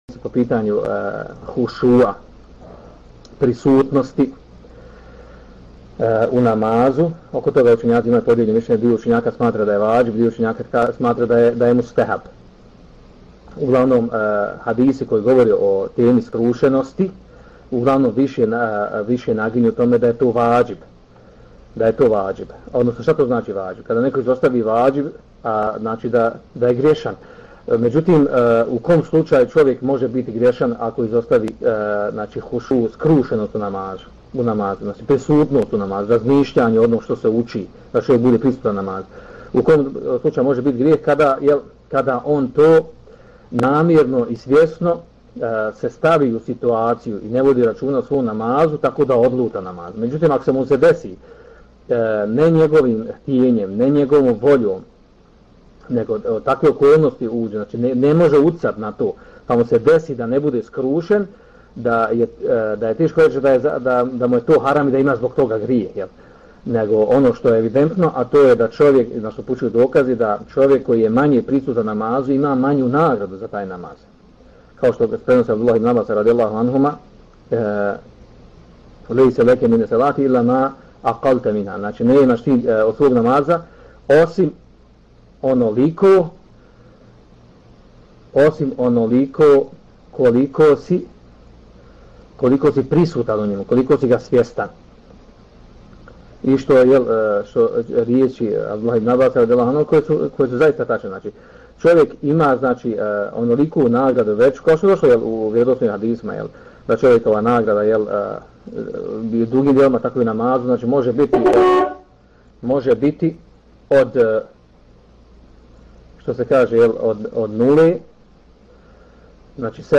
Poslušajte audio isječak iz predavanja